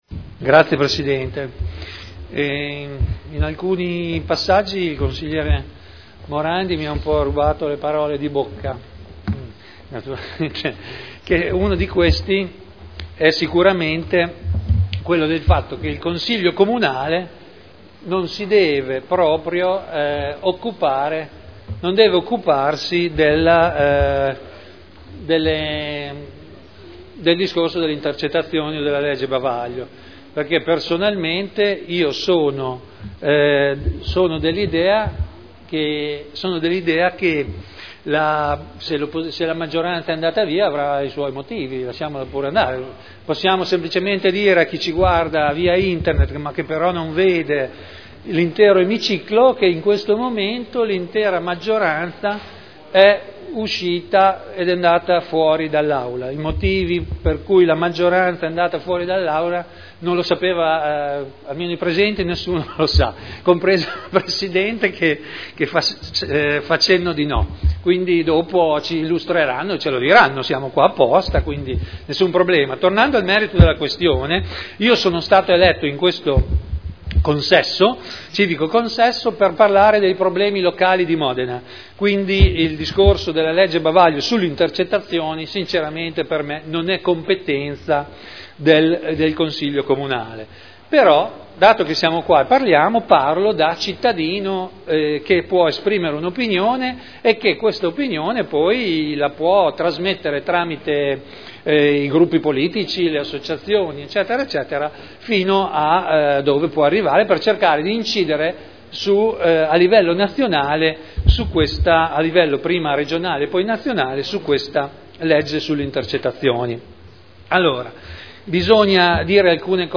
Seduta del 07/03/2011. Ordine del giorno avente per oggetto: “No alla “legge Bavaglio”, sì ad una seria e completa informazione e ad una giustizia imparziale” – Primo firmatario consigliere Trande Discussione